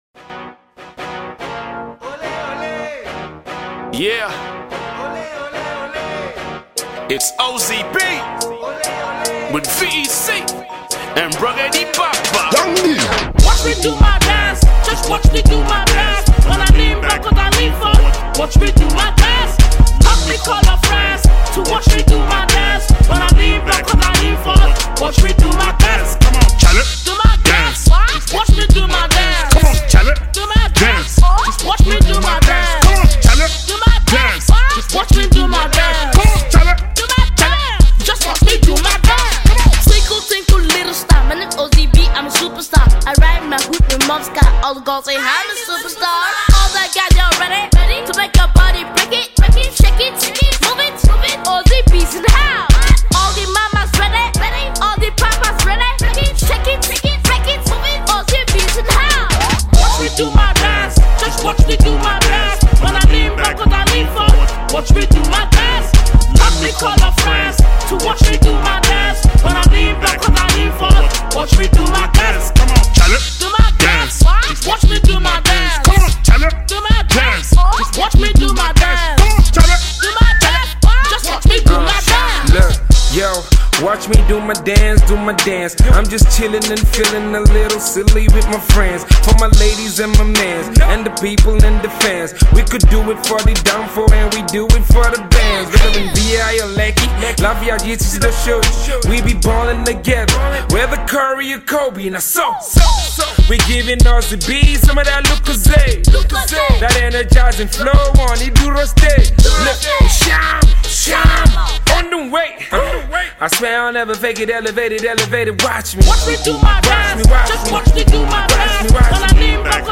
Alternative Pop
dance track